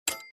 minislot_stop_2.mp3